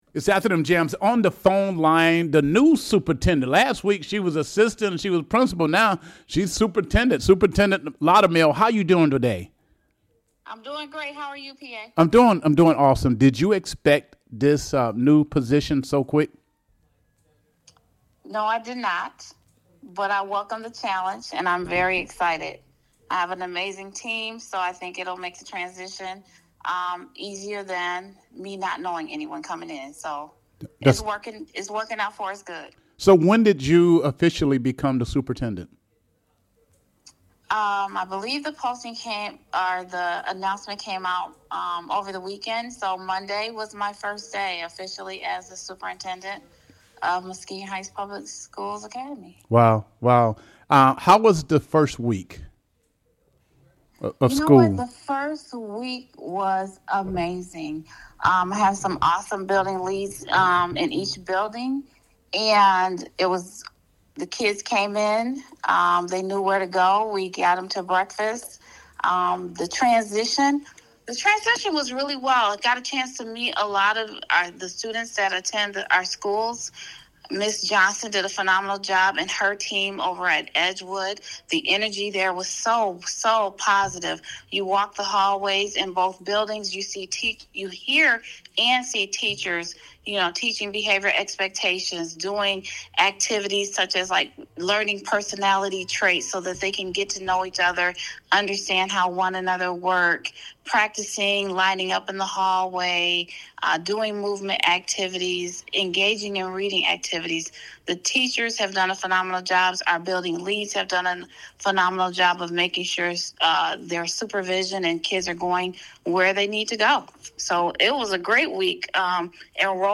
Interview with Muskegon Heights’ newly-appointed Superintendent LaKisha Loudermill